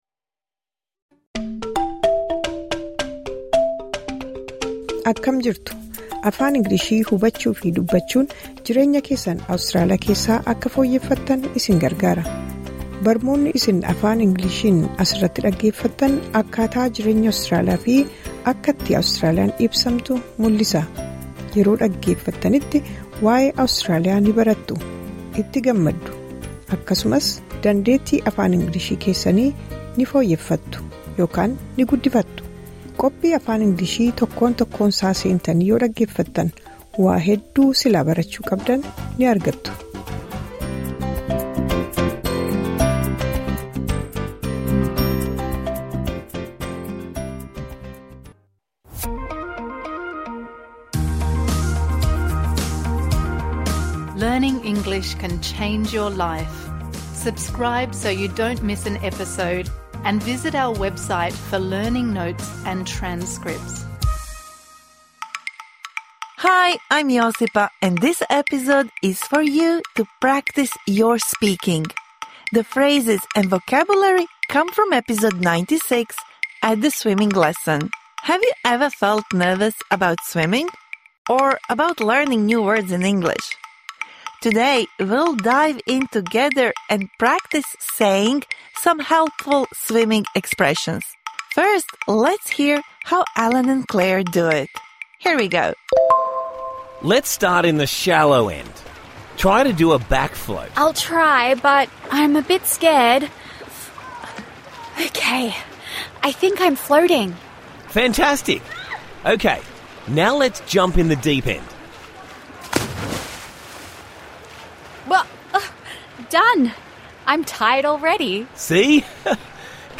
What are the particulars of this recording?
This bonus episode provides interactive speaking practice for the words and phrases you learnt in #96 At a swimming lesson (Med).